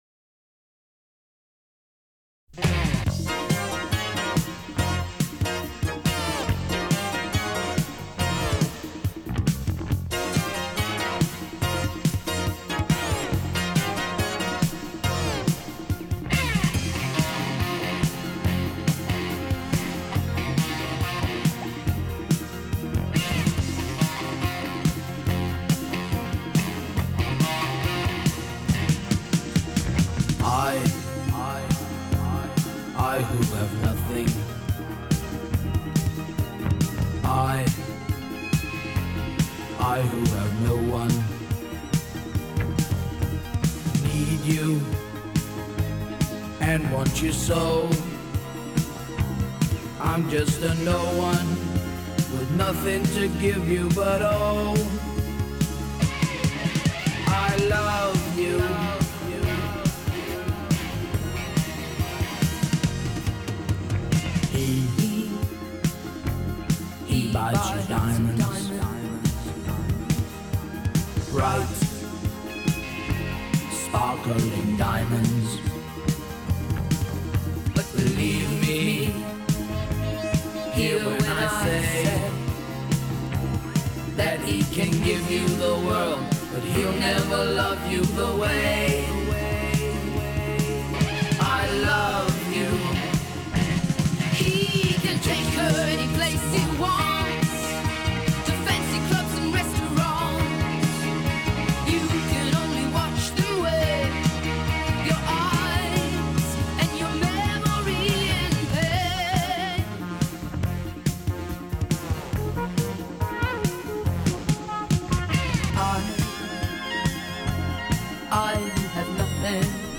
Жанр: арт-рок, Хард-рок
Style:Pop Rock